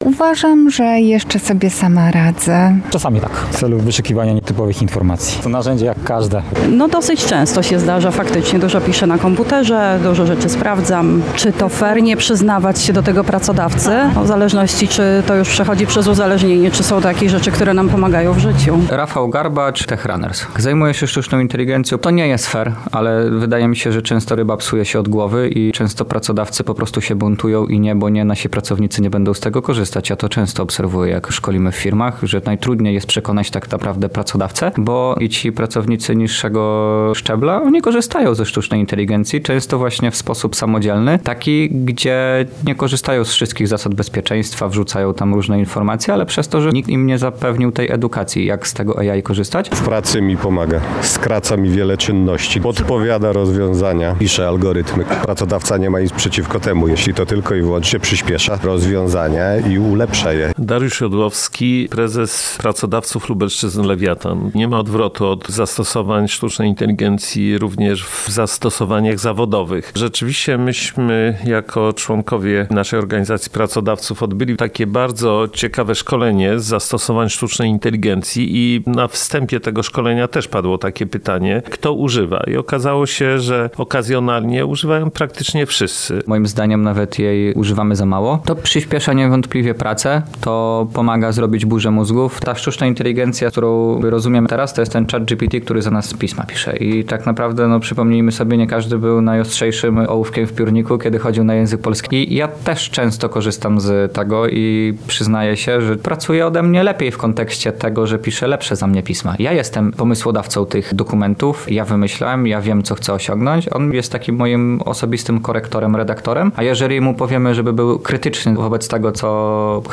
O to, czy takie zachowanie jest w porządku wobec pracodawcy, zapytaliśmy mieszkańców Lublina.